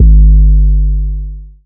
DDW6 808 9.wav